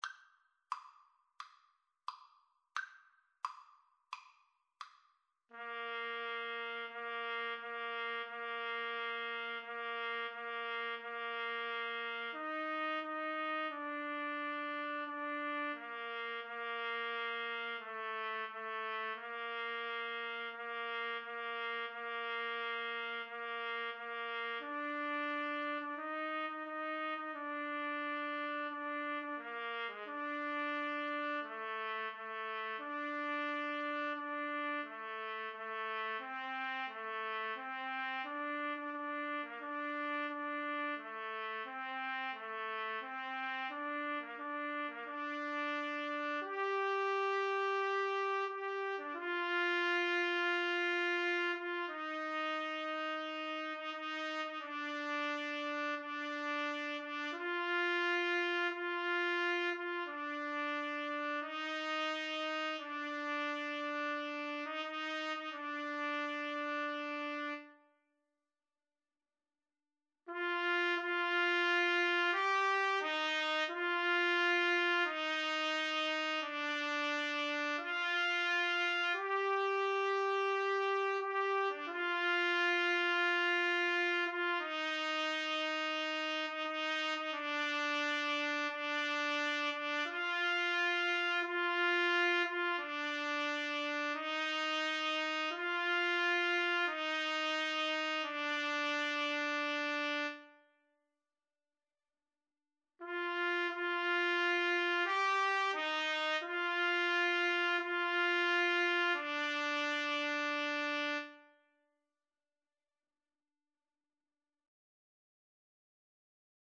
Andante =c.88
Trumpet Duet  (View more Intermediate Trumpet Duet Music)